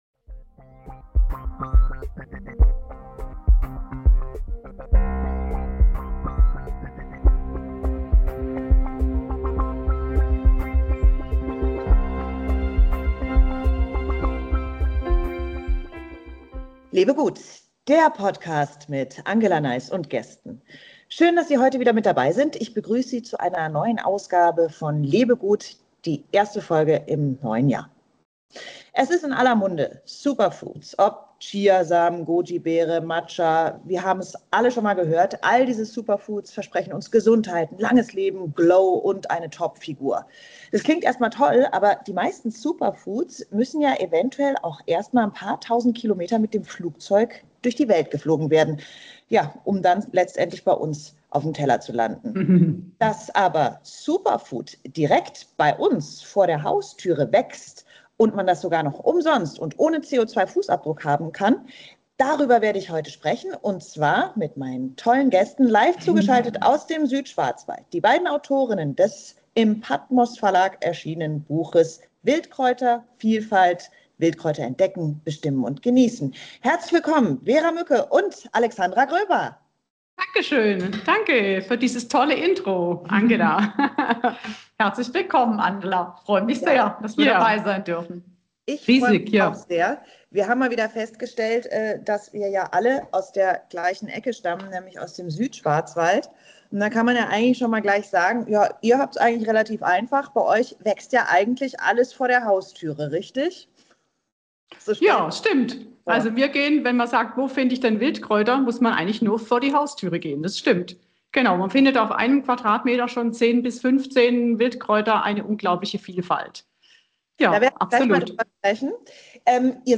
live aus dem Südschwarzwald